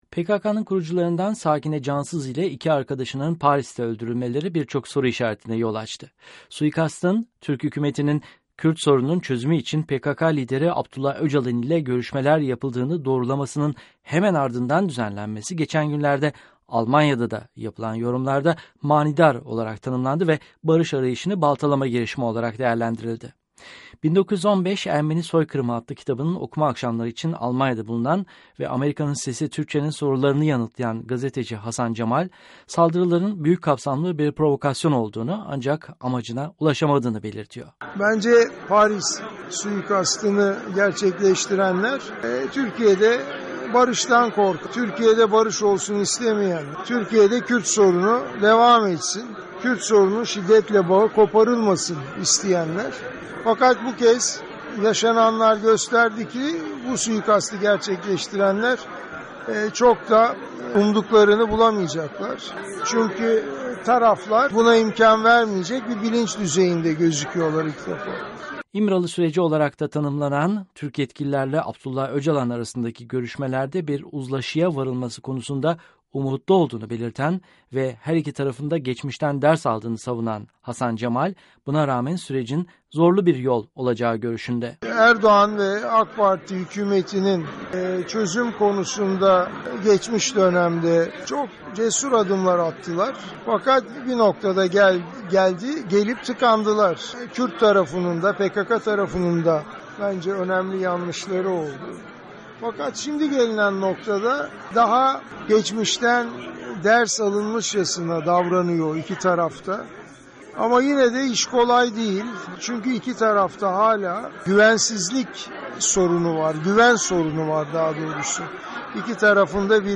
söyleşisi